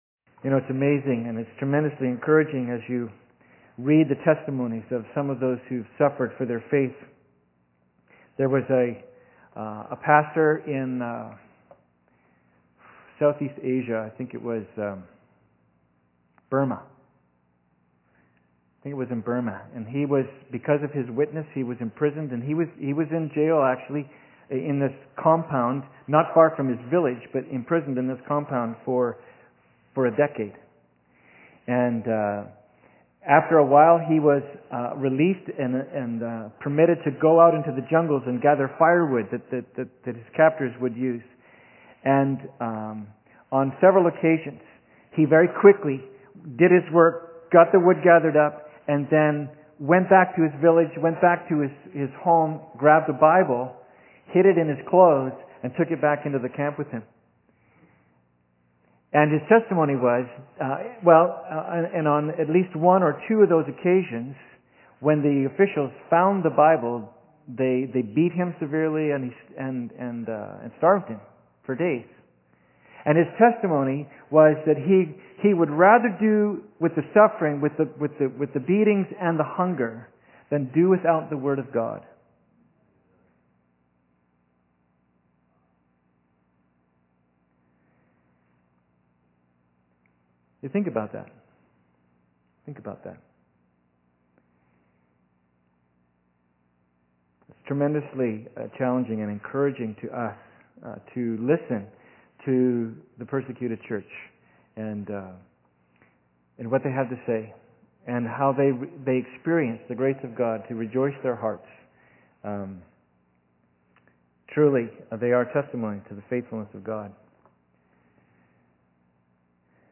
Passage: Matthew 6:9 Service Type: Sunday Service
Sermon_11.2.m4a